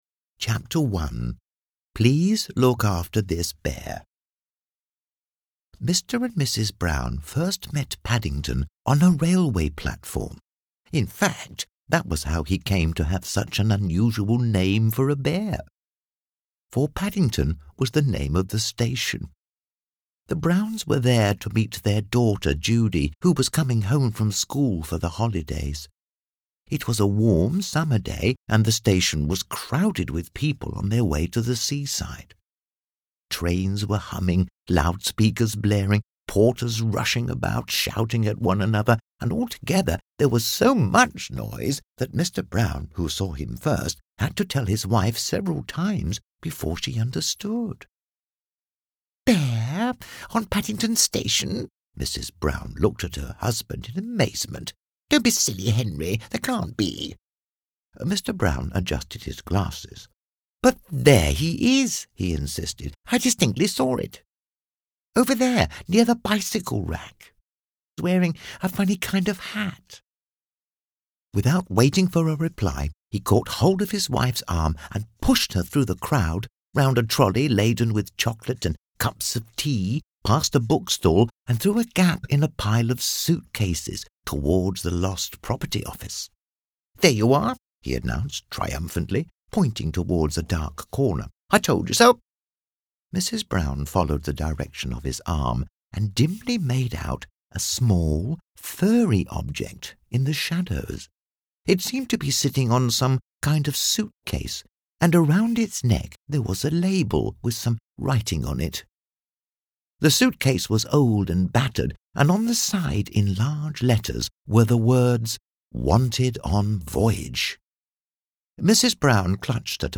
Children's Story
This narration is particularly suited to children's books because of the tone of the narrator.
Accent: English